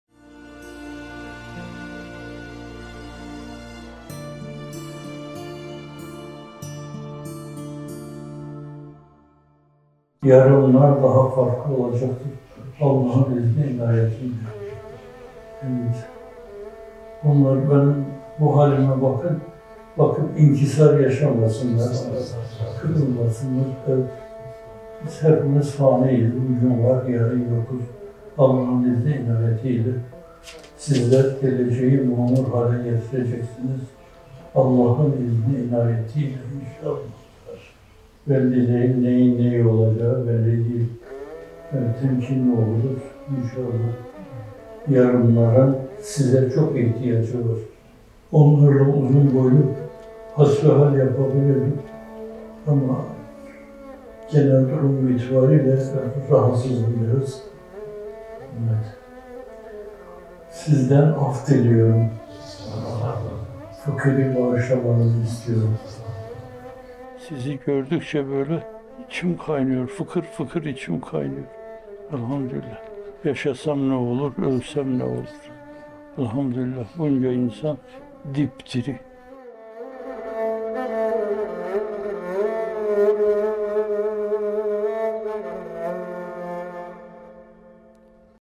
Muhterem Hocamız’ın yakın tarihli bir sohbetinden…